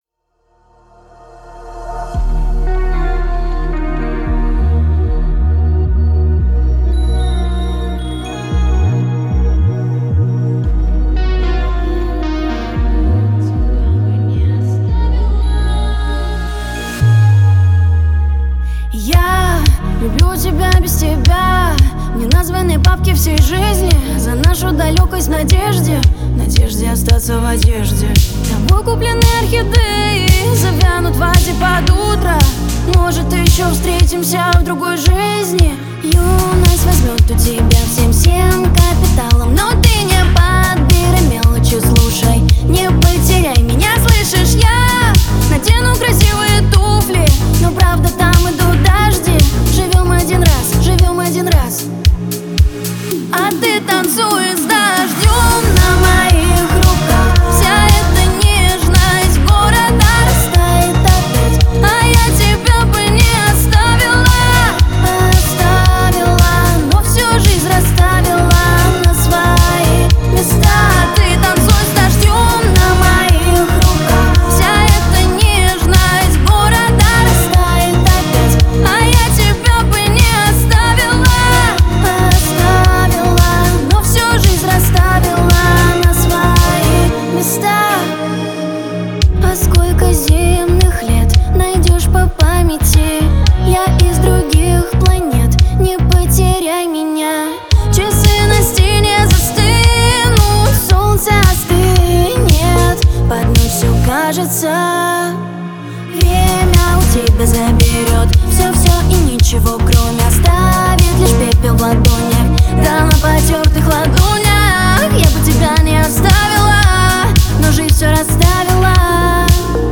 Хаус музыка